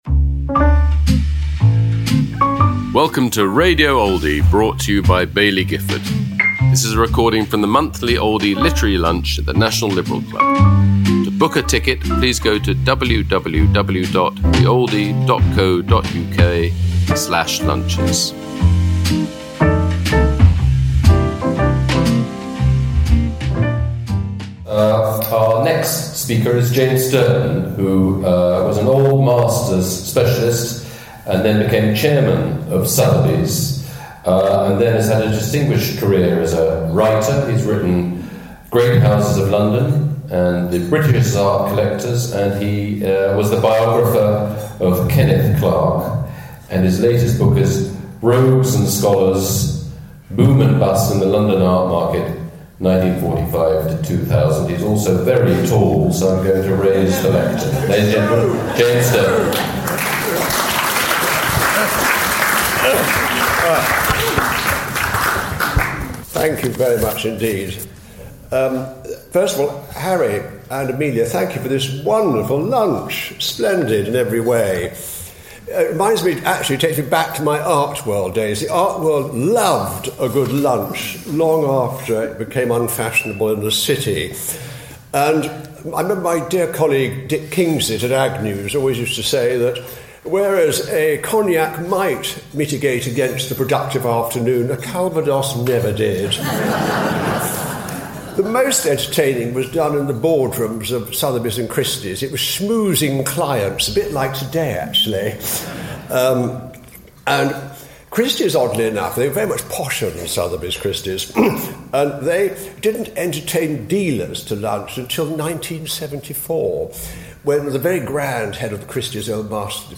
at the Oldie Literary Lunch, held at London’s National Liberal Club